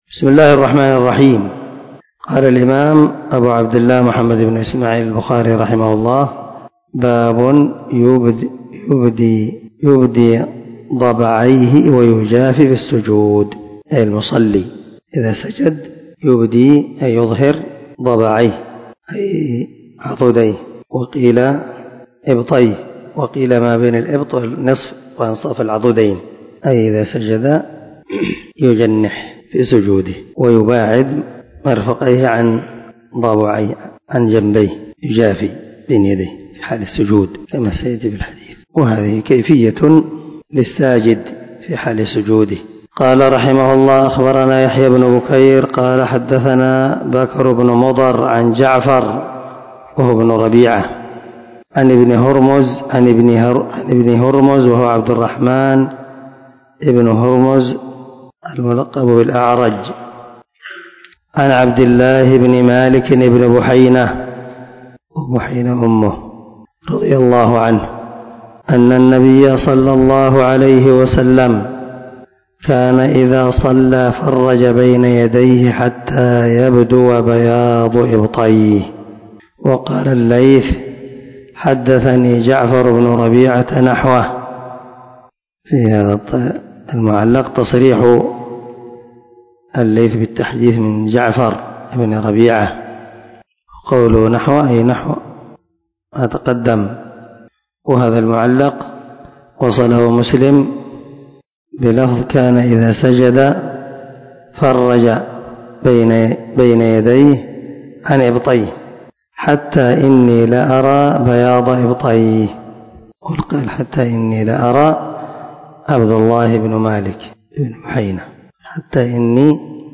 519الدرس 102 من شرح كتاب الأذان حديث رقم ( 807 ) من صحيح البخاري